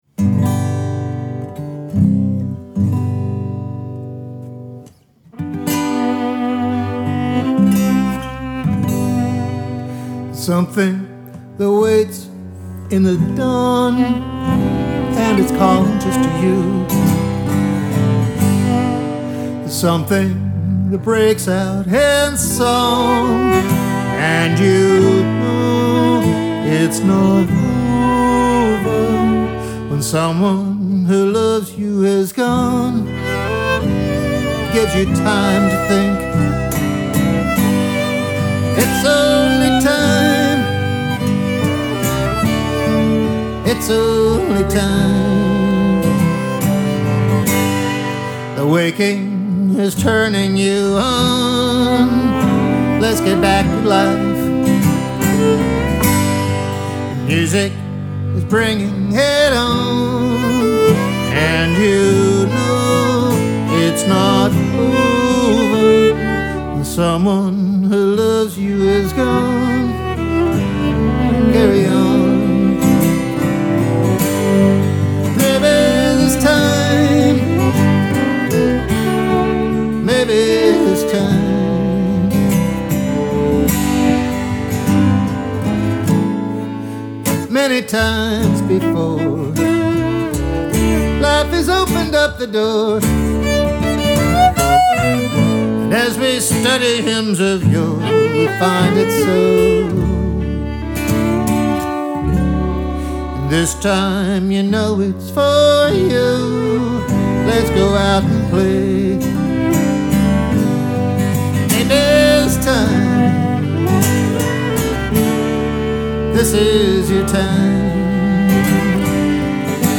From the album - not the choral version